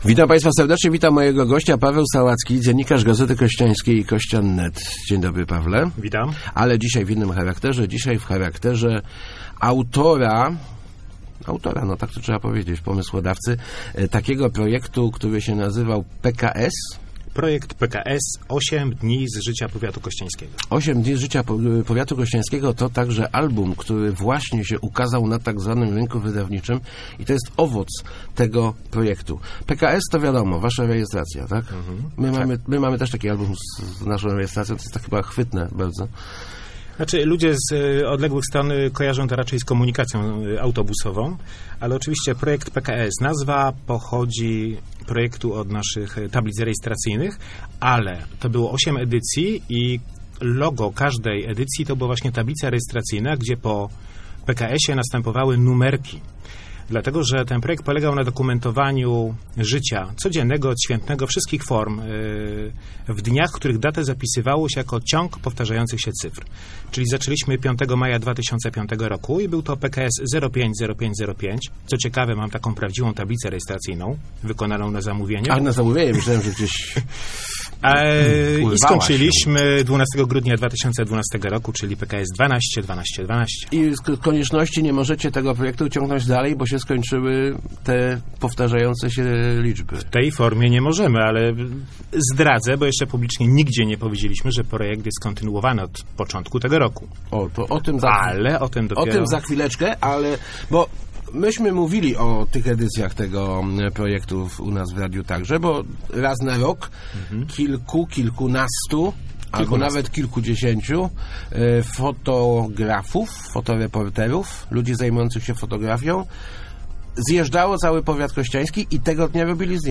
Start arrow Rozmowy Elki arrow Projekt PKS w albumie